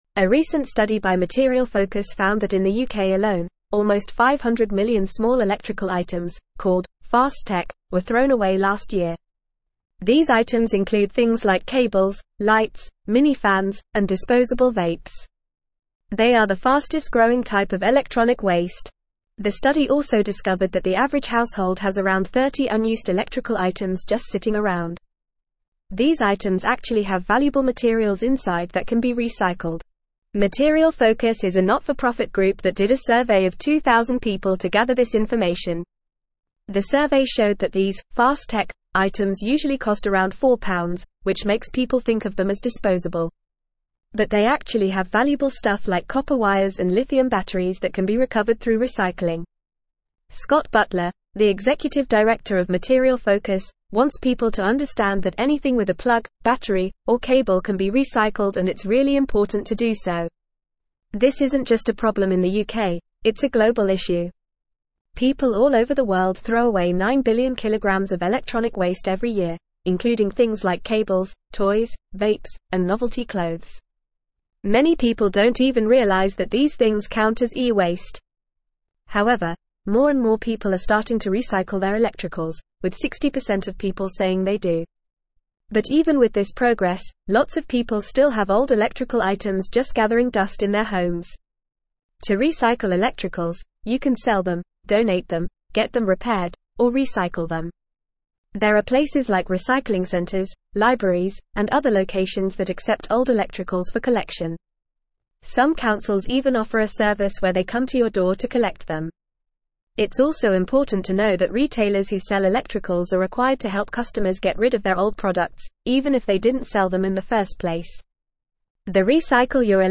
Fast
English-Newsroom-Upper-Intermediate-FAST-Reading-UK-Urged-to-Recycle-Fast-Tech-E-Waste.mp3